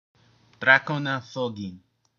Listeni/ˈzɑˌkw.nɑ ˈtˌɡn/, Drak.: ɒʊ̆ɞɷɤɔ ɾ̆ɜȷɤ; Drakona thogin; Drakona pronunciation:
Listeni/ˈdrɑˌk.nɑː ˈðˌɡɪn/) is the alphabet used to represent the three Old World Drakona languages -- Drakona, Edazoran Drakona, Lyoonos -- and single New World language -- Eloonis.